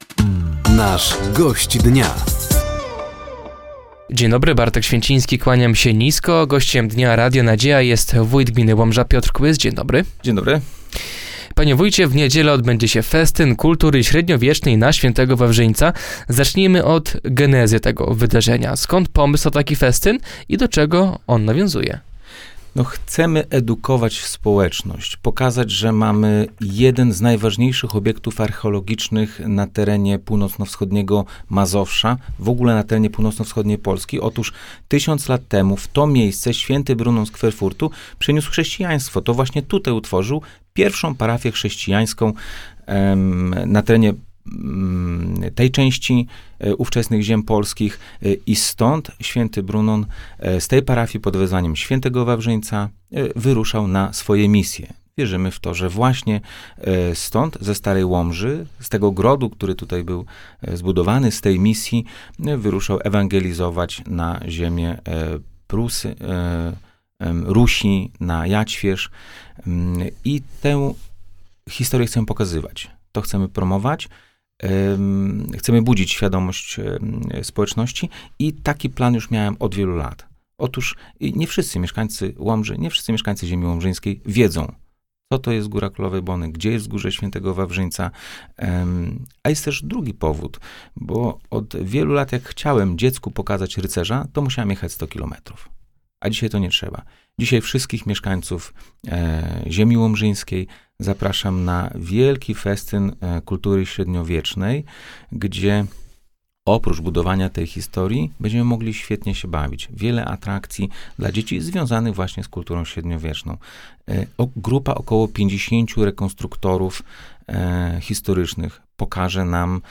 Gościem Dnia Radia Nadzieja był wójt gminy Łomża, Piotr Kłys. Tematem rozmowy był między innymi zbliżający się Festyn kultury średniowiecznej na św. Wawrzyńca i inwestycje drogowe w gminie.